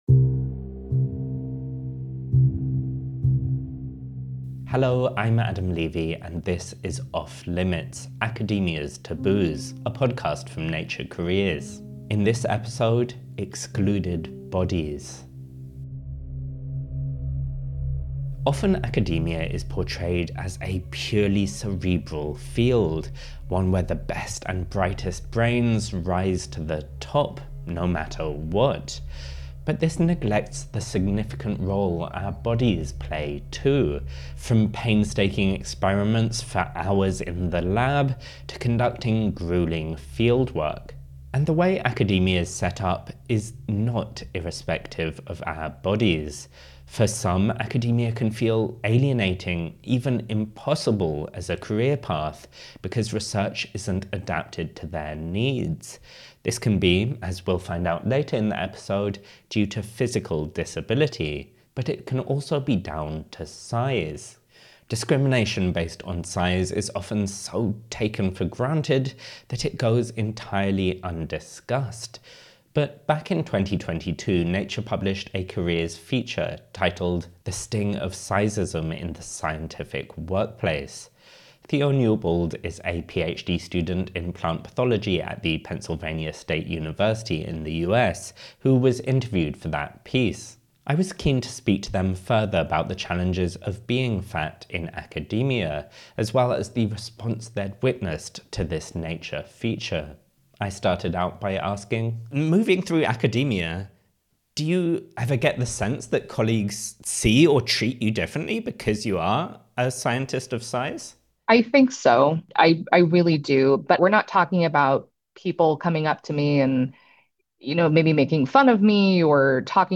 Two researchers describe how body size and having a disability can challenge perceptions of what a scientist should look like.